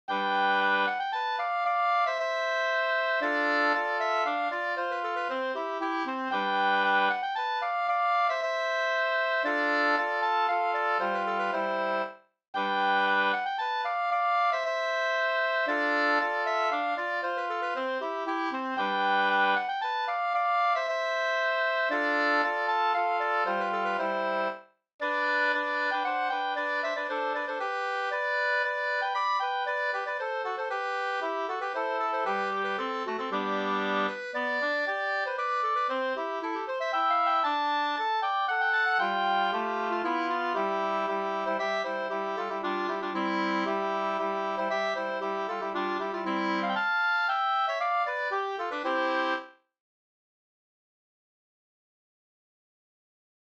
66 Soluppgång över Dellen, brudpolska efter "From-Olle", Olof Jonsson From, Järvsö, Hälsingland,
polska Soluppgang över Dellen e From-Olle 2st.mp3